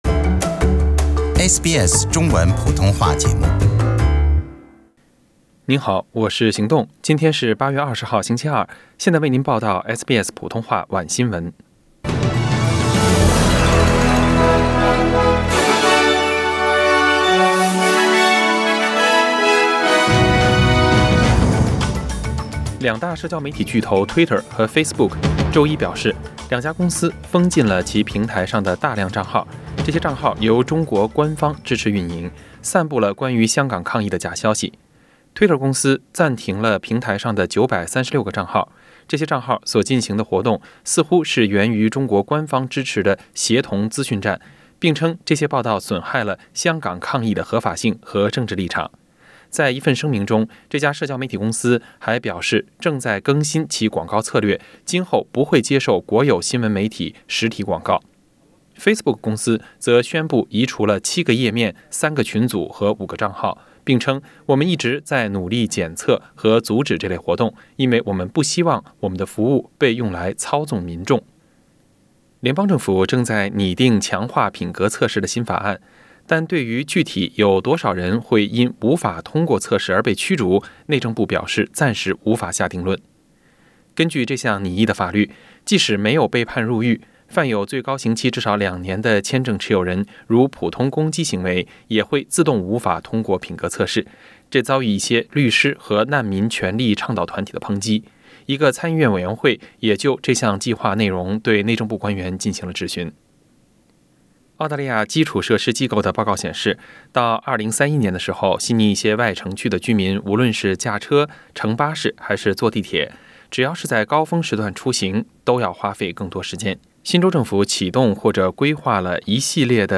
SBS晚新闻 （8月20日）